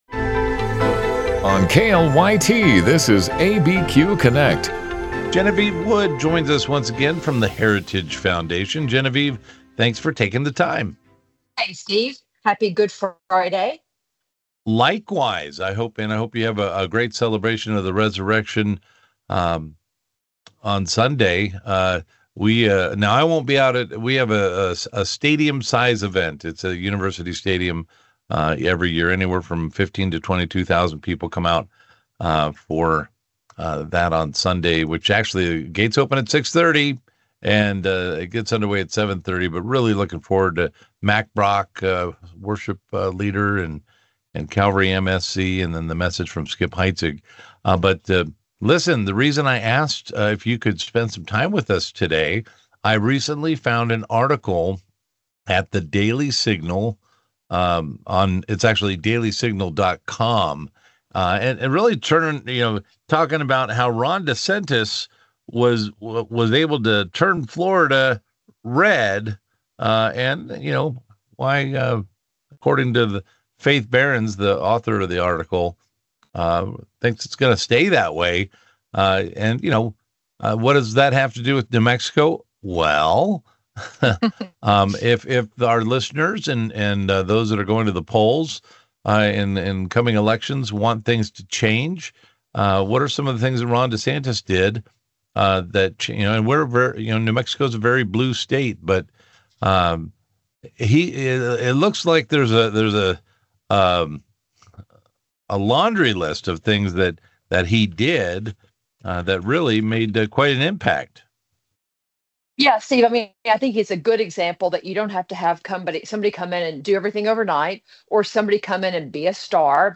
Albuquerque's live and local call-in show.